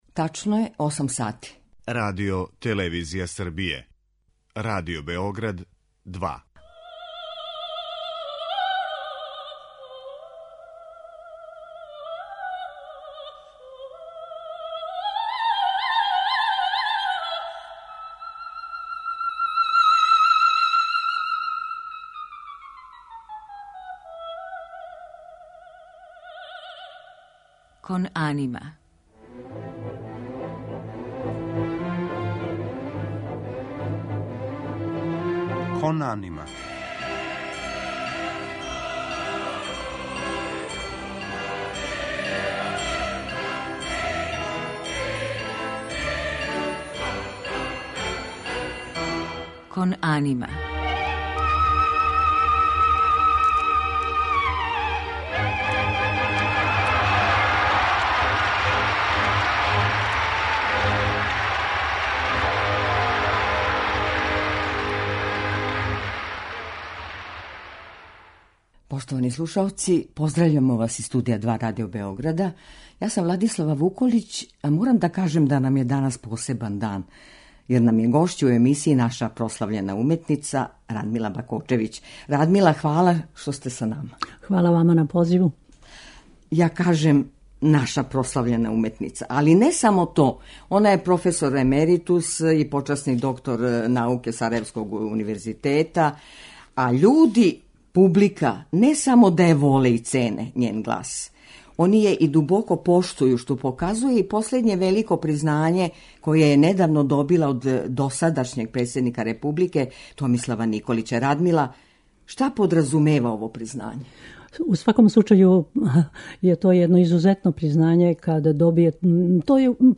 Гошћа емисије је наша прослављена оперска уметница Радмила Бакочевић.
У музичком делу биће емитоване арије из опера Ђузепа Вердија, Ђакома Пучинија и Петра Коњовића.